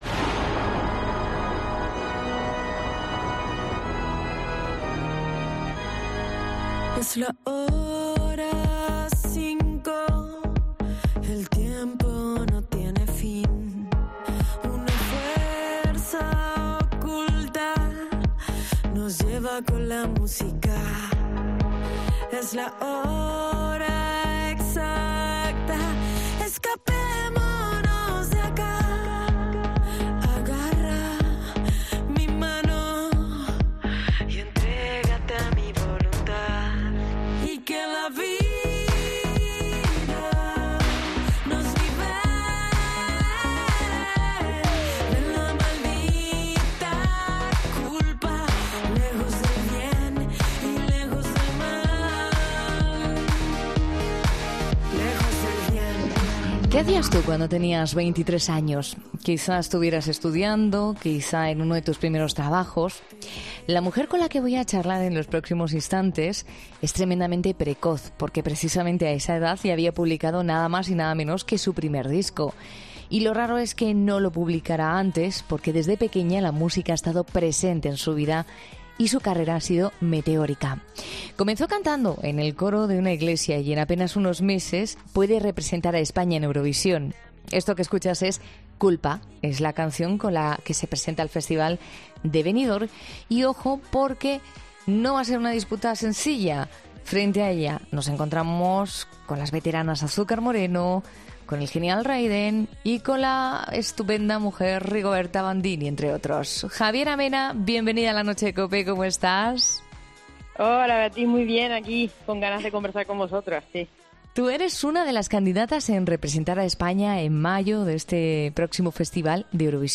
Voy a dar lo mejor de mí, pero me lo estoy pasando muy bien”, explicaba en La Noche de COPE.